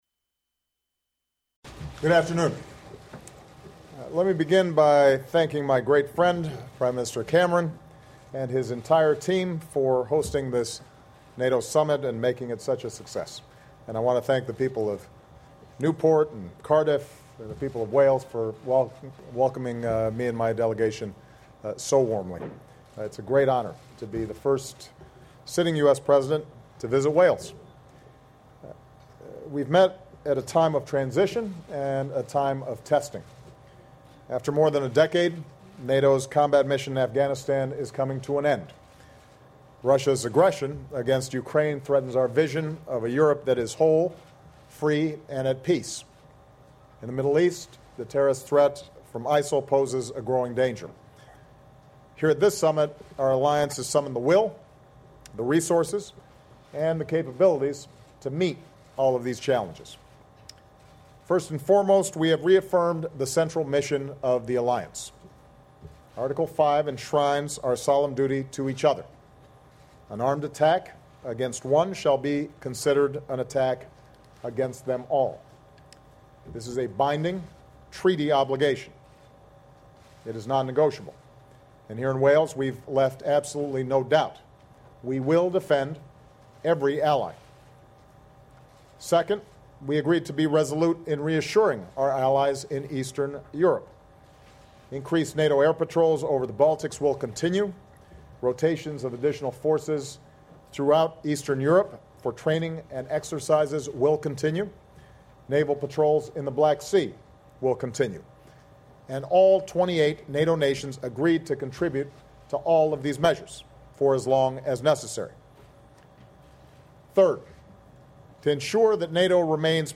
U.S. President Barack Obama speaks with reporters and responds to questions following the conclusion of the NATO Summit in Wales